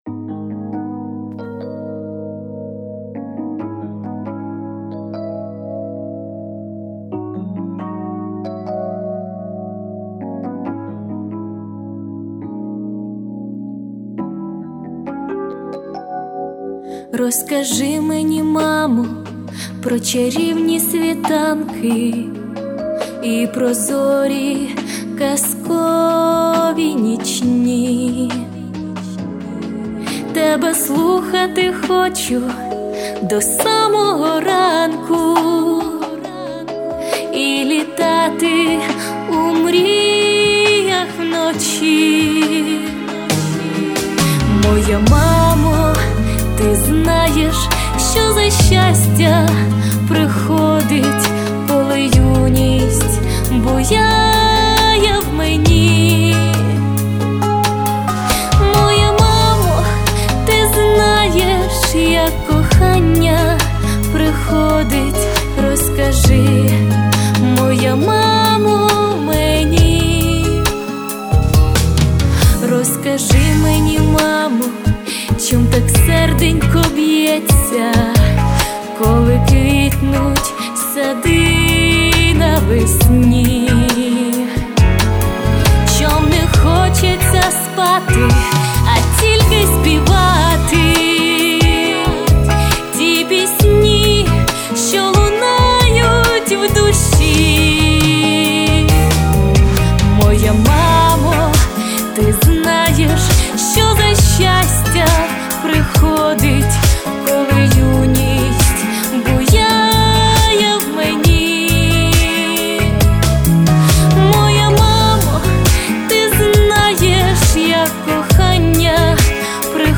Мінусовка
Темп : Помірна Виконується: Соло Стать : Жіноча